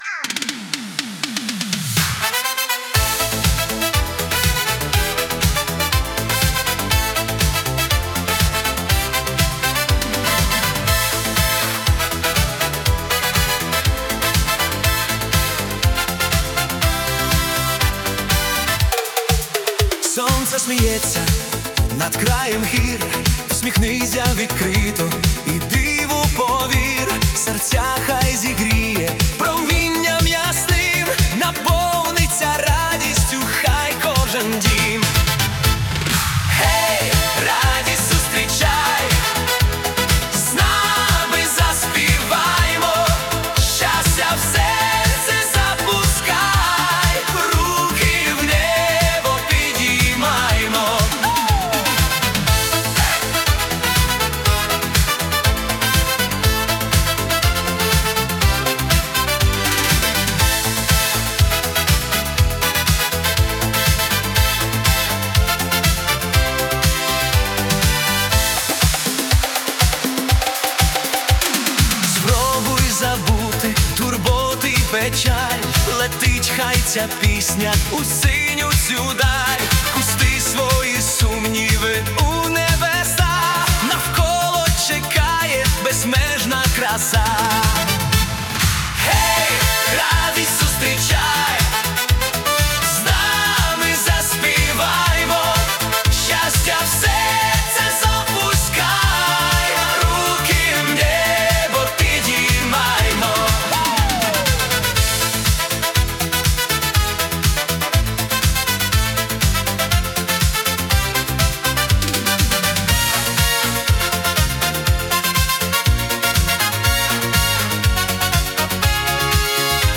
Euro-Disco Енергійний танцювальний хіт.